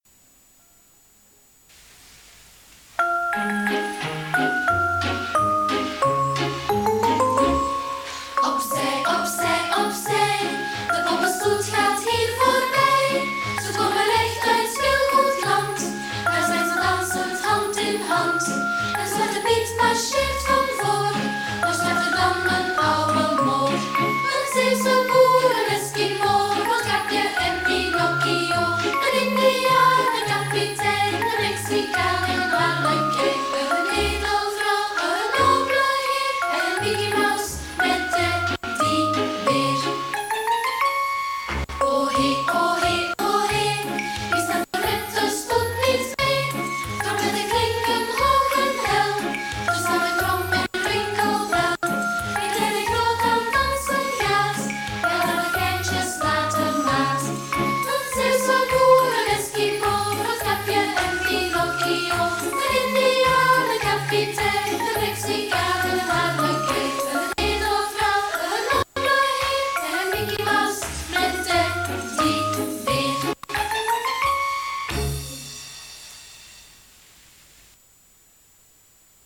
Kinderen
Vlaanderen